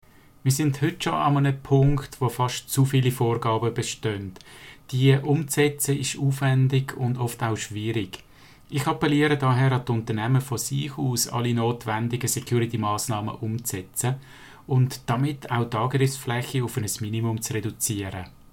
Dieses Interview gibt es auch auf Hochdeutsch!